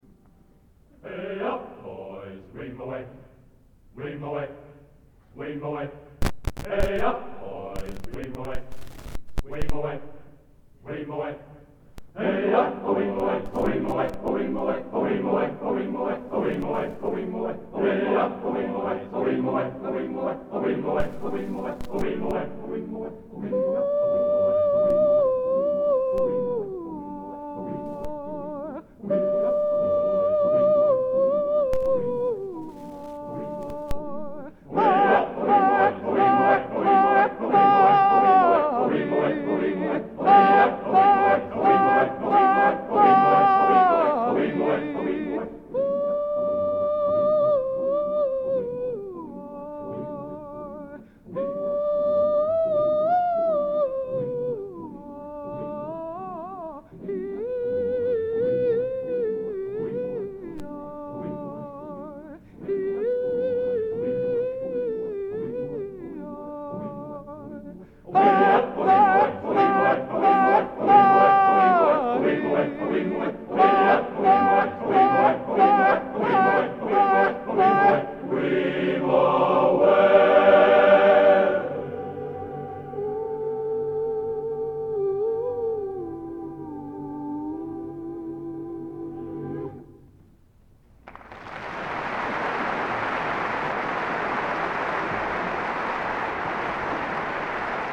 Genre: Traditional | Type: